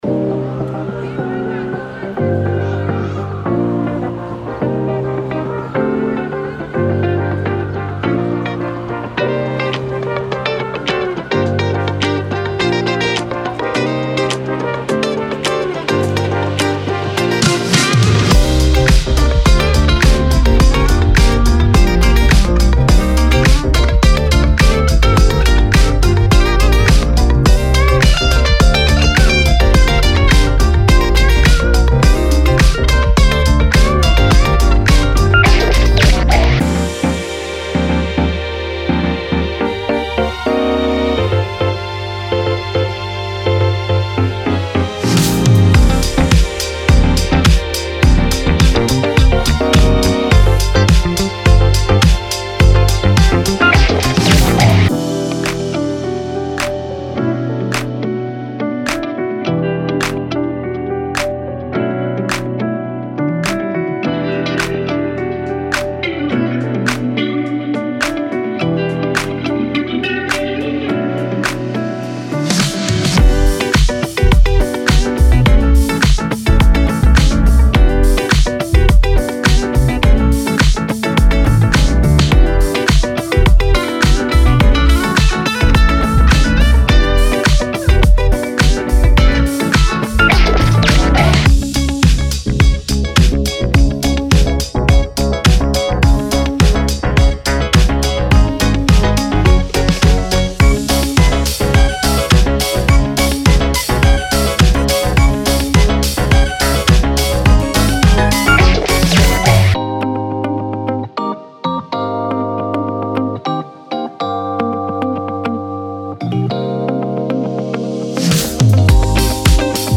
Genre:Disco
デモサウンドはコチラ↓